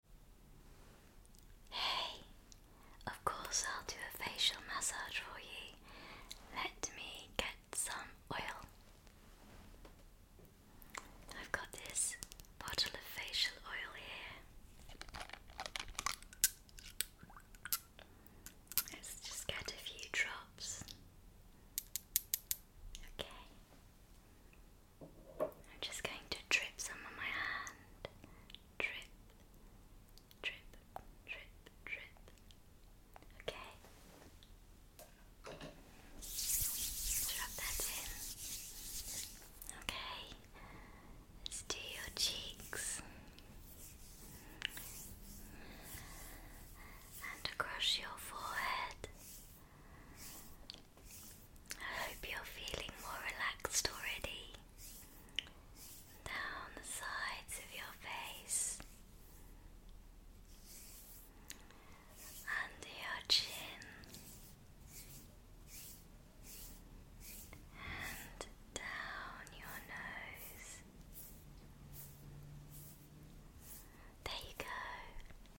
Enjoy my British English ASMR whisper whilst I massage your face